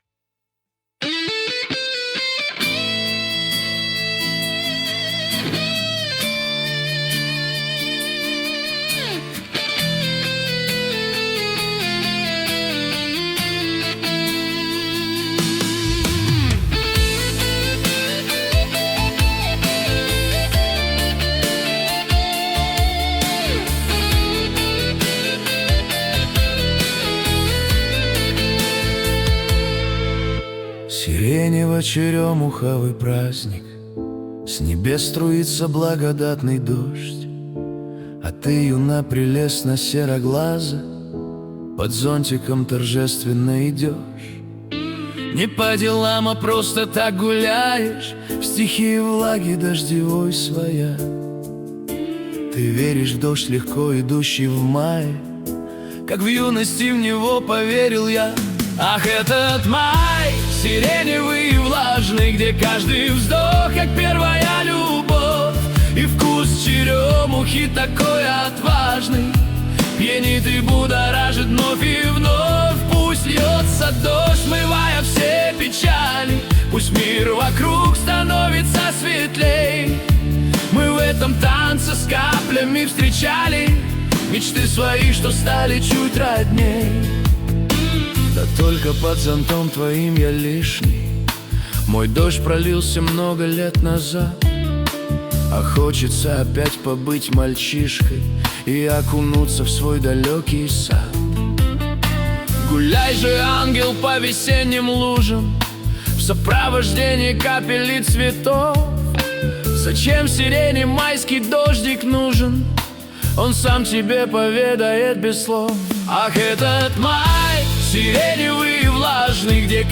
Качество: 320 kbps, stereo
Русские поп песни